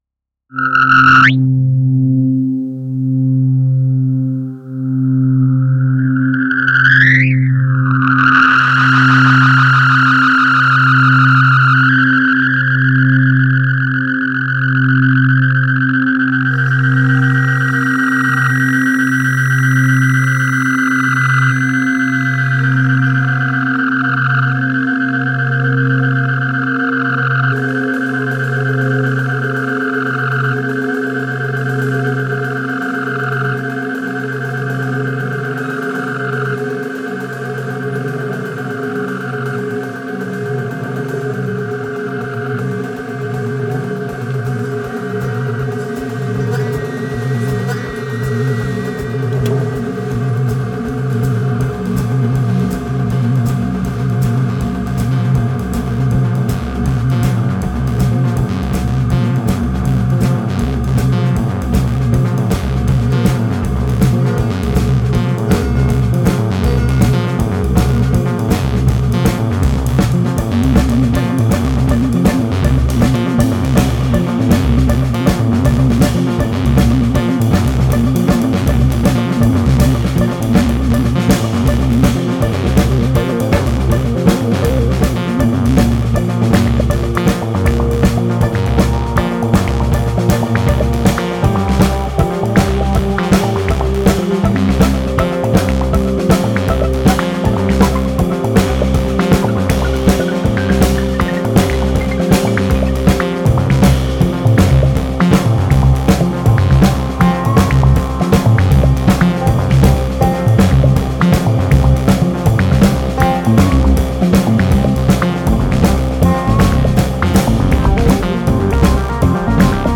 68 Minuten Electroblaukraut in acht Spuren.